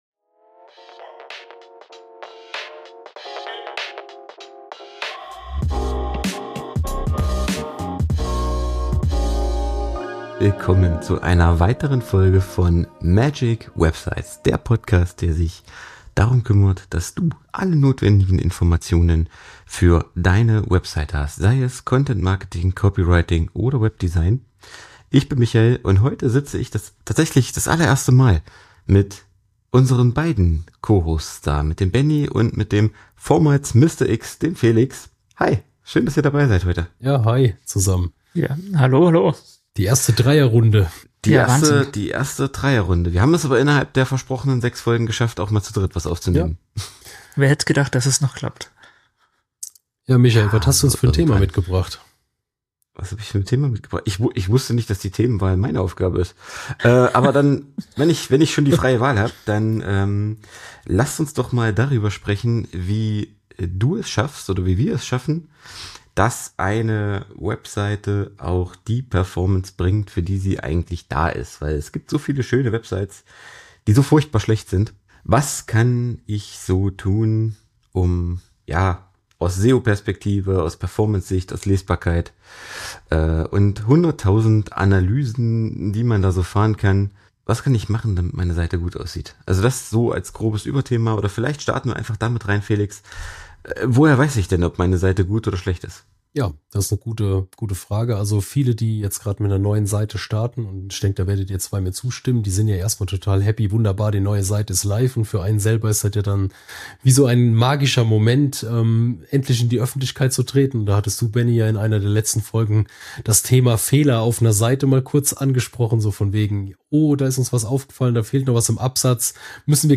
Beschreibung vor 1 Jahr Der Podcast über Content Marketing, Storytelling, Copywriting und Webdesign - Du erfährst alles, was es für eine magische Webseite braucht. Jeder der drei Hosts steht für einen eigenen Bereich und eine individuelle Perspektive auf das Thema Webseiten.